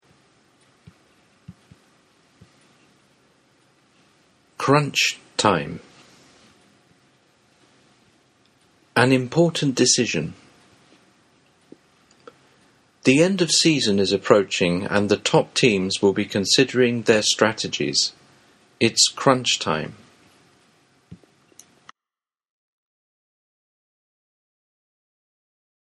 よって crunch time とは、一生懸命、スピーディに行動することが求められる試練の時いう意味で、 とくにスポーツの試合のラストやビジネスなどの締め切り直前と言った状況でよく使われる表現です。 ネイティブによる発音は下記のリンクをクリックしてください。